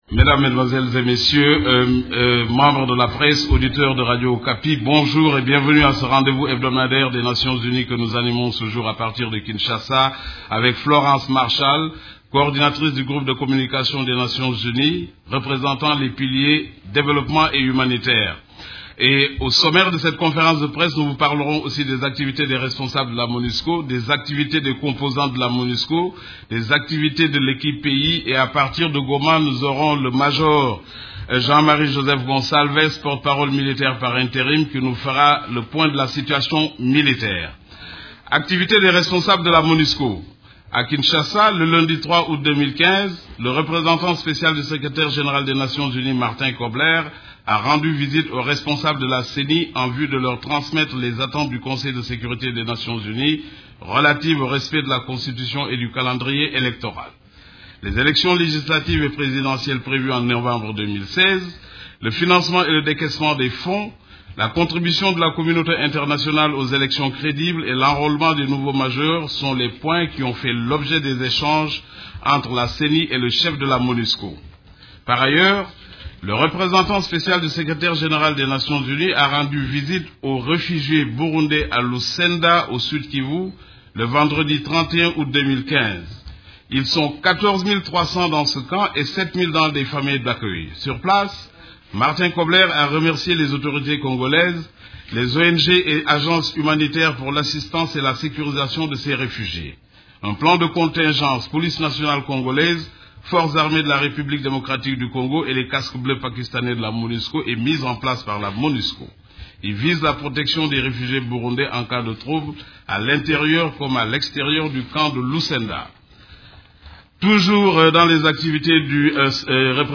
Conférence de presse du 5 août 2015